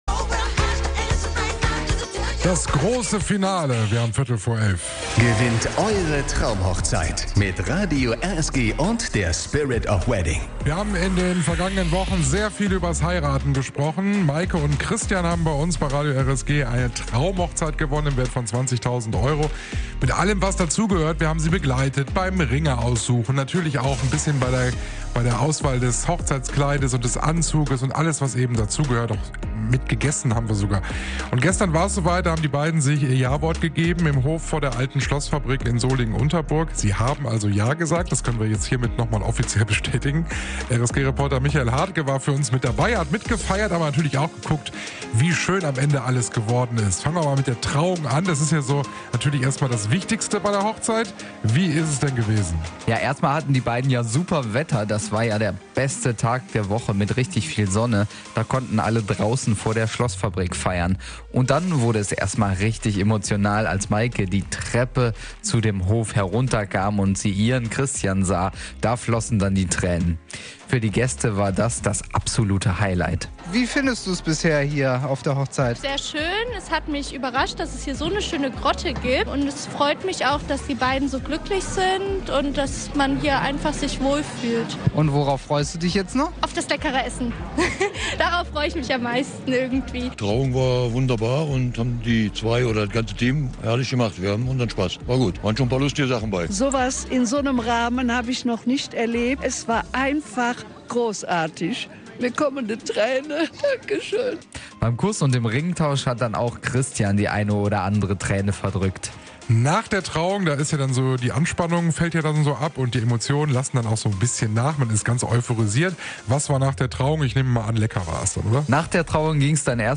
TraumhochzeitDie Hochzeitsfeier